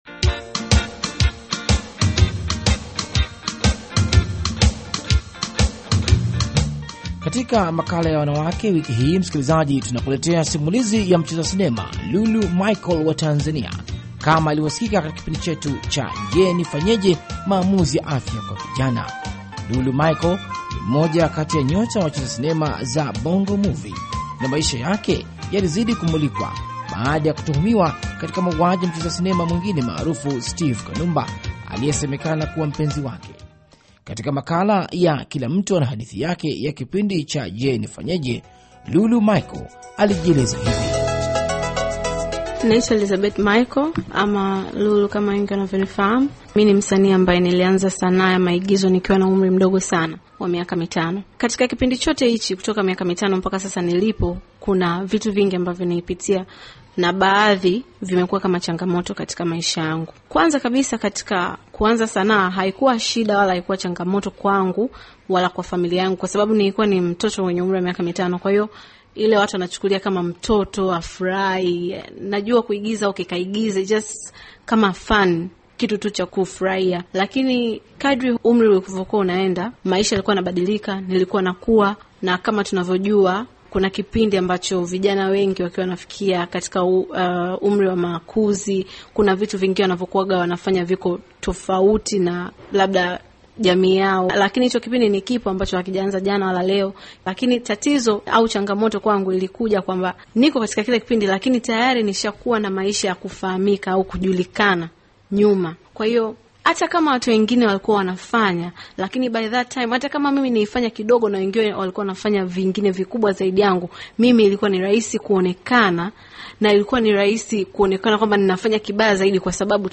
Wanawake: Mahojiano